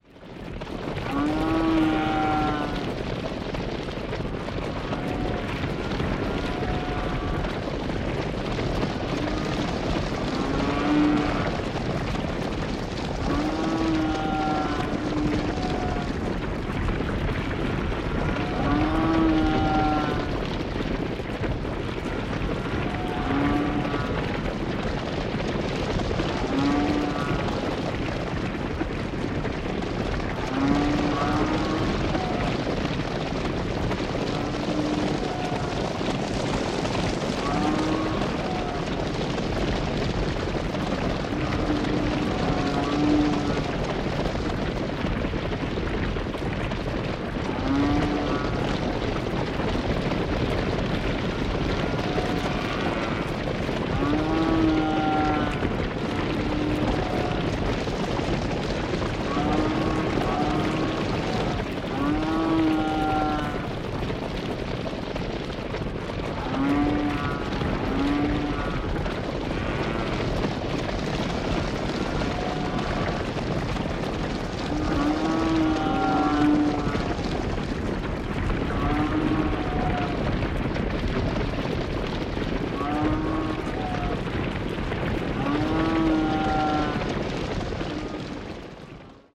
Громкие, ритмичные удары копыт создают эффект присутствия – используйте для звукового оформления, релаксации или творческих проектов.
Звук спешащих быков